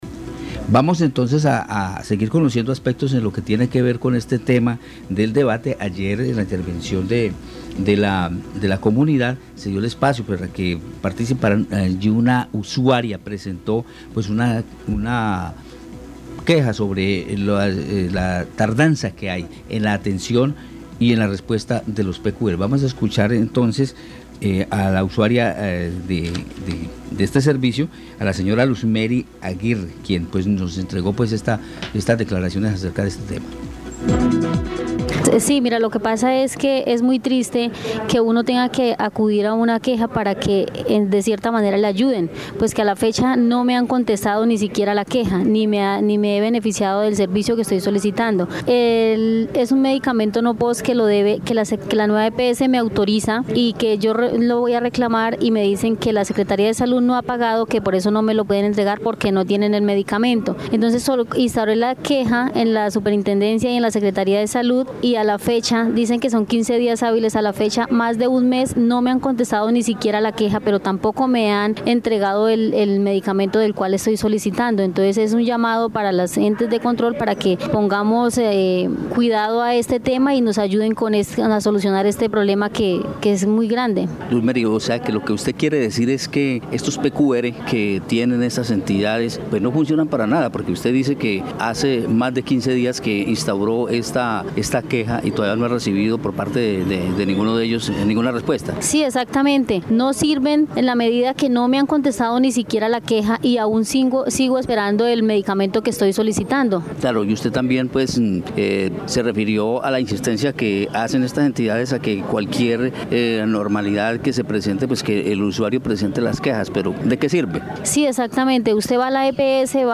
Debate control político a la salud